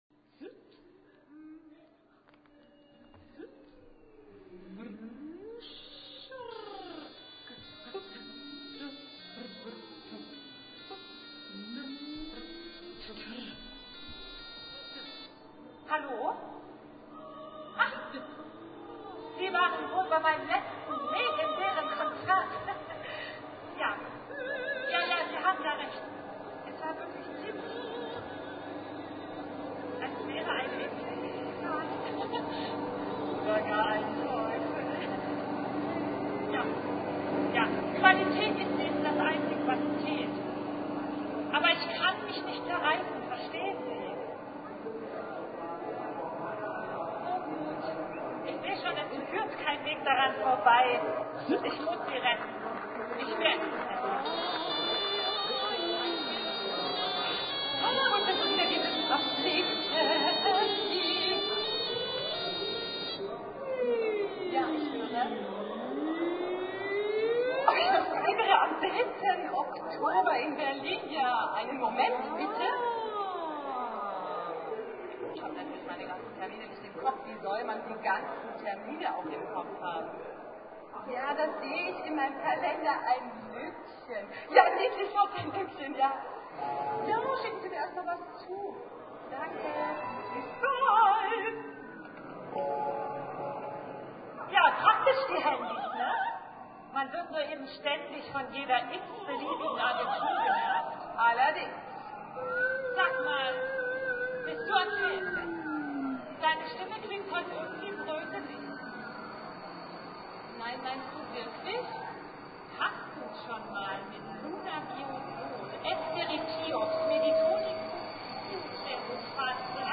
für 2 hysterische Sängerinnen und Tonband
Besetzung: Sopran, Mezzosopran, Tonband 2000